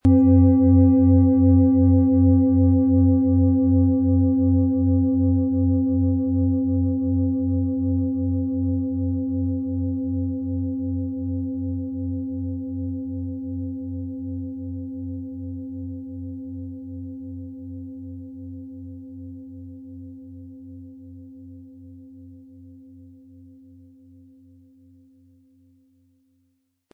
Planetenton
Durch die traditionsreiche Herstellung hat die Schale stattdessen diesen einmaligen Ton und das besondere, bewegende Schwingen der traditionellen Handarbeit.
Der gratis Klöppel lässt die Schale wohltuend erklingen.
MaterialBronze